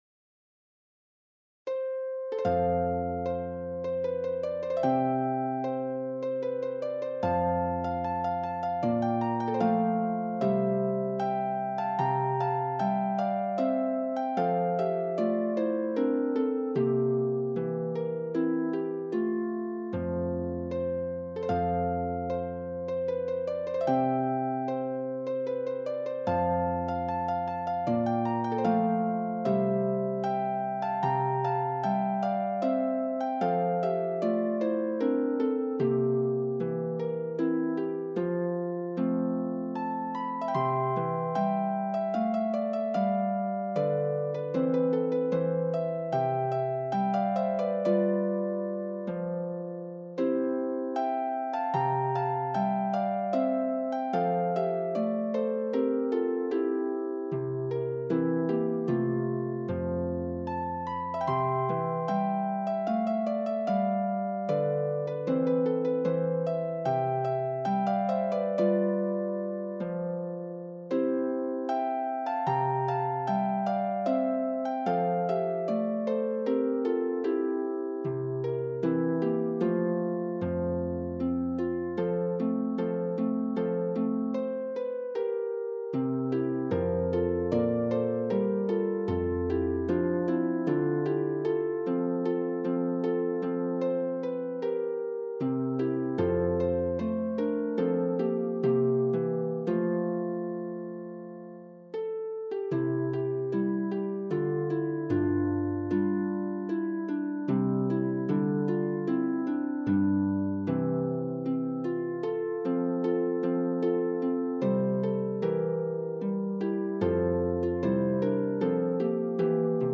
traditional Bavarian tune